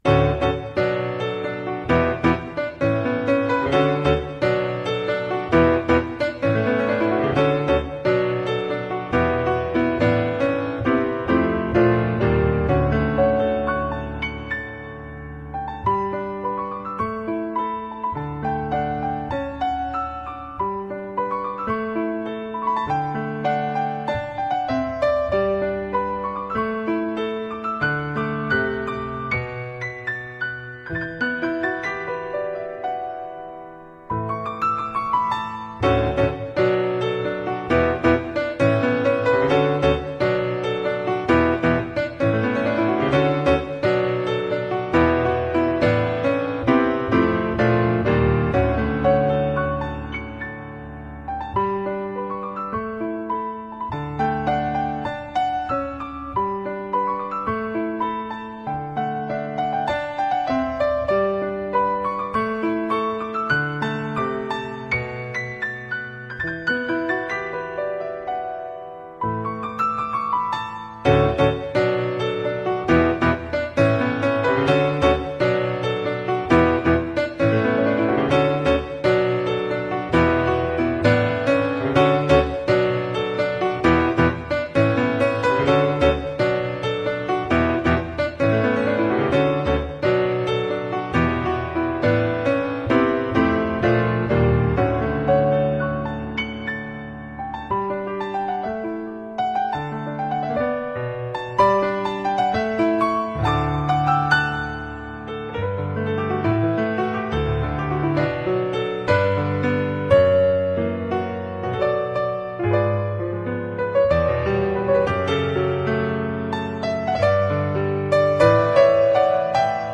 Ayuda me encantó esta cancioncita, no tiene letra, pero el piano y la emotividad dicen mucho.